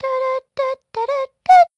Isolated from the other two voice clips